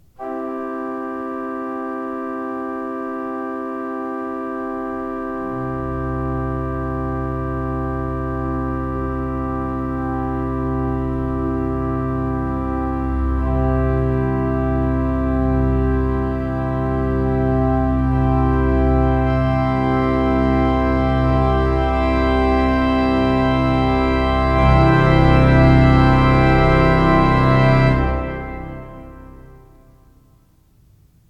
The Schuelke Pipe Organ
Samples of each were taken from the floor and the loft to illustrate subtle differences in the sound. In general, the loft clips are clearer while more of the church's resonance is heard in the floor clips.
Crescendo with Sforzando - This clip is the same as the Crescendo clip except that the Sforzando is activated at the end.
stmarycc-crescendo_with_sforz_loft.mp3